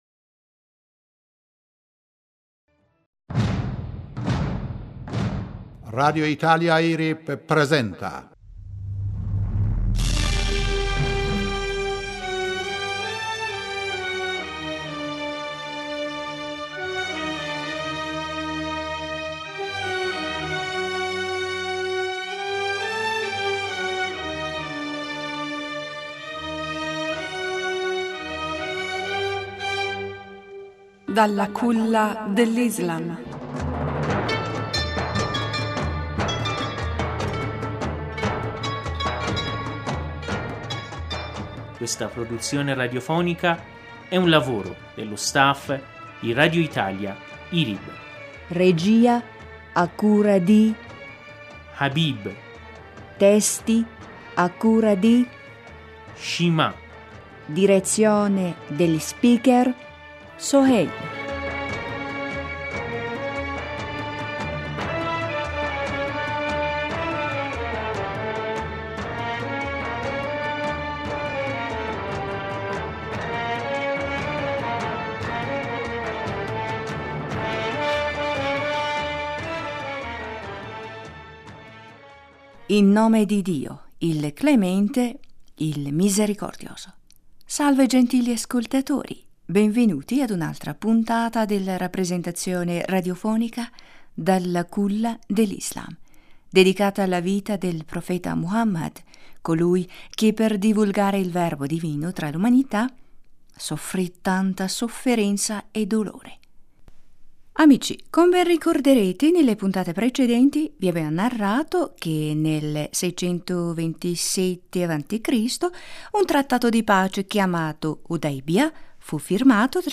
PARSTODAY-In nome di Dio, il Clemente, il Misericordioso. Salve gentili ascoltatori, benvenuti ad un’altra puntata della rappresentazione radiofonica “Dall...